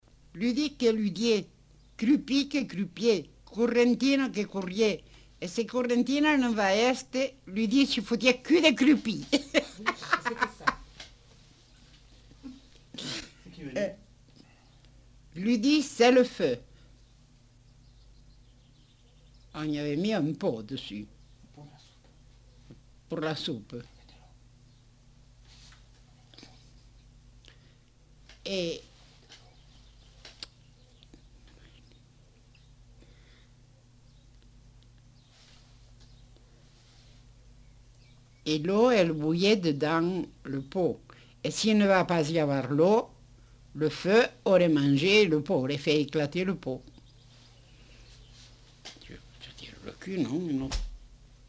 Lieu : Montauban-de-Luchon
Type de voix : voix de femme
Production du son : récité
Classification : mimologisme